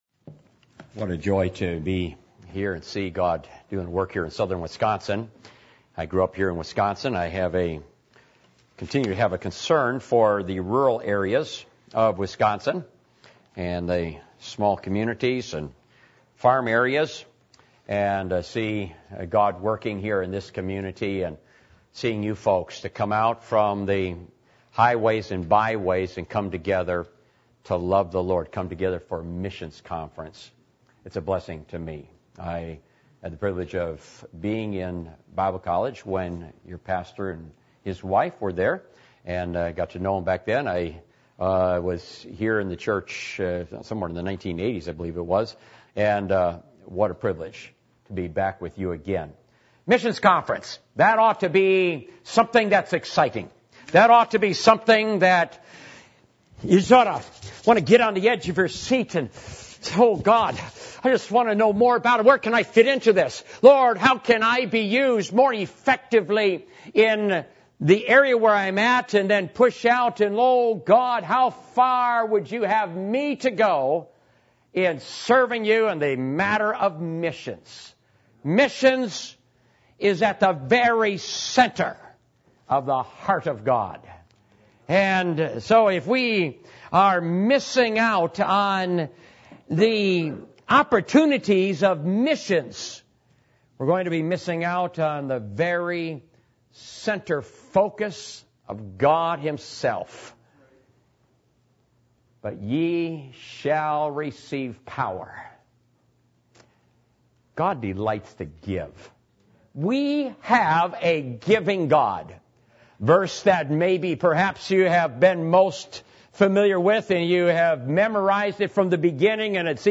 Passage: Hebrews 11:4-6, Hebrews 11:17, Deuteronomy 6:1-14, Deuteronomy 6:11-12 Service Type: Missions Conference